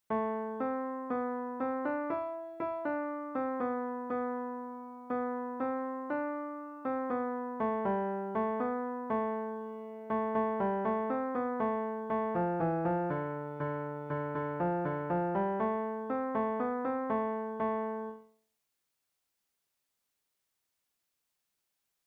Tenori